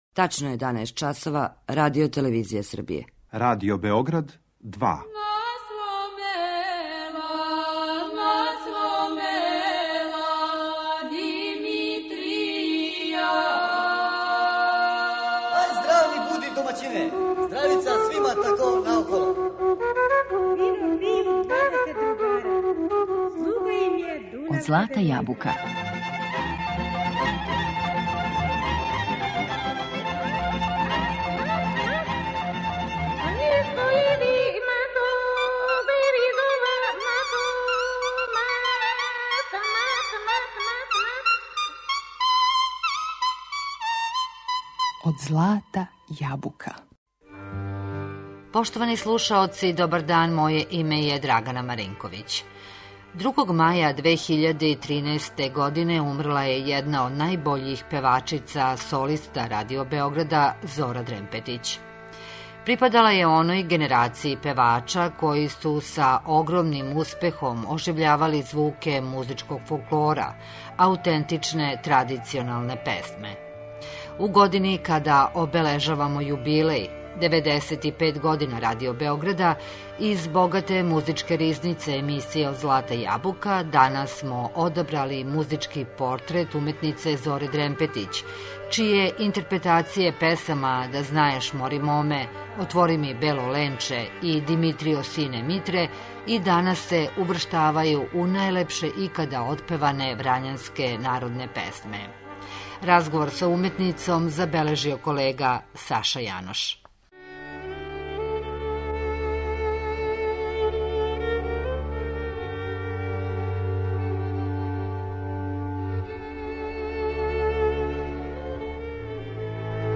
Музички портрет
припадала је генерацији солиста Радио Београда који су са огромним успехом оживљавали звуке музичког фолклора аутентичне традиционалне песме.
Постала је солиста 1952. године, а њене интерпретације песама „Да знаеш, мори, моме", „Дуде, бело дуде", „Димитријо, сине Митре" и др. и данас се сврставају у најлепше отпеване врањанске народне песме.